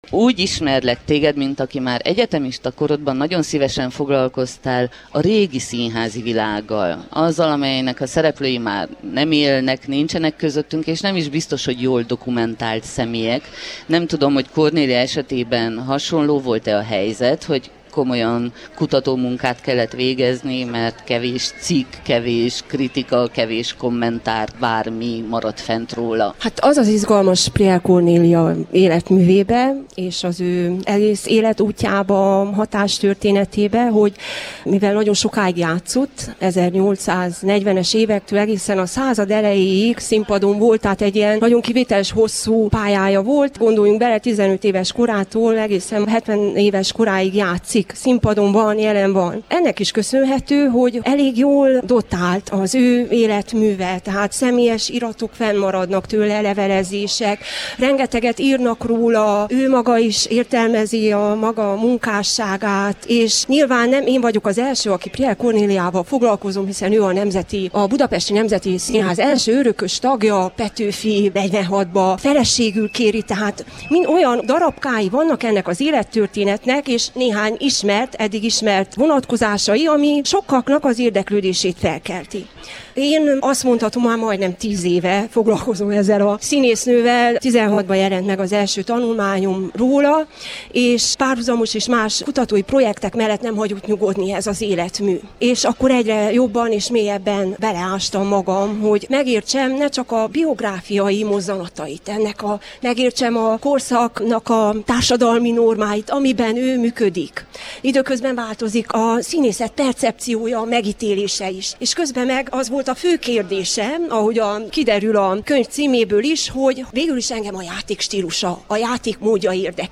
interjúja
a Kolozsvári Rádió mobil stúdiójában.